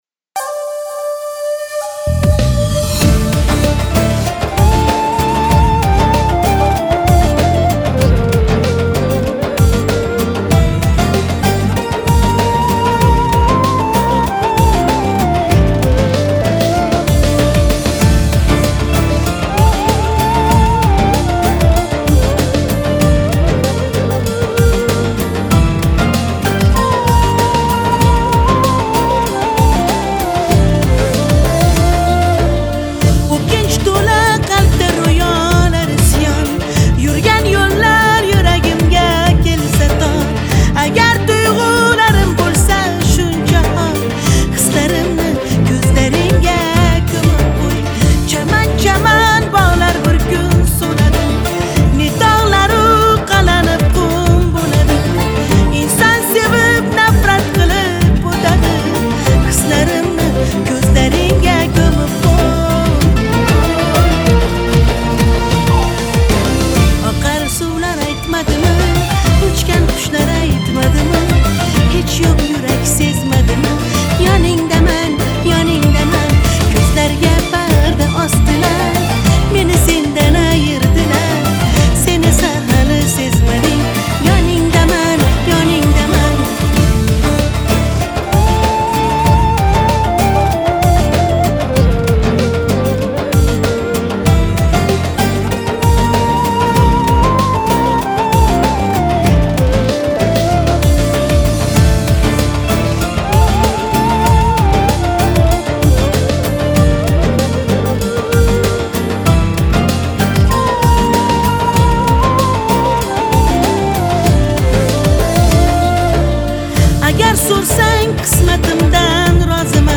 • Жанр: Зарубежные песни